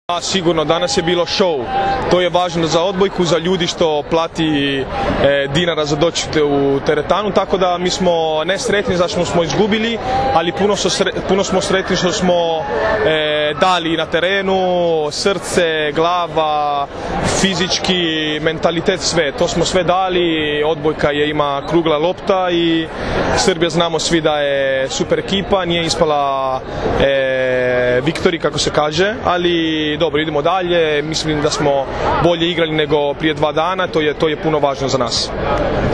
IZJAVA DRAGANA TRAVICE